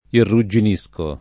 irrugginire
vai all'elenco alfabetico delle voci ingrandisci il carattere 100% rimpicciolisci il carattere stampa invia tramite posta elettronica codividi su Facebook irrugginire v.; irrugginisco [ irru JJ in &S ko ], ‑sci